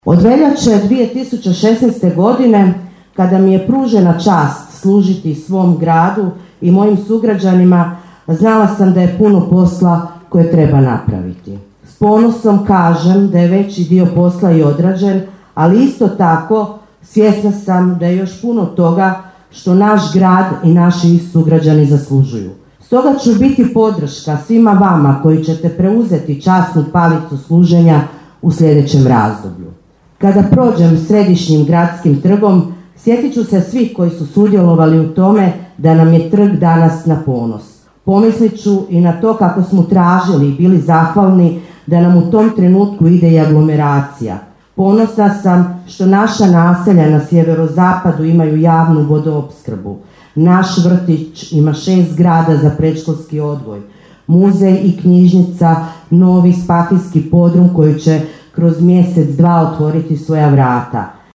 Svečana sjednica Gradskog vijeća Pakraca upriličena je u povodu Dana Grada Pakraca u Hrvatskom domu dr. Franjo Tuđman u Pakracu.
Dovoljan je bio samo trenutak da zadrhti glas a čelična lady pokaže krhkost i drugu stranu svoje osobnosti, naglašenu osjećajnost.
Govor na svečanoj sjednici gradonačelnice Pakraca Anamarije Blažević bio je posvećen ostvarenjima za Pakračanke i Pakračane a zbog kojih je današnja gradonačelnica koja više ne ide u izborne utrke ponosna.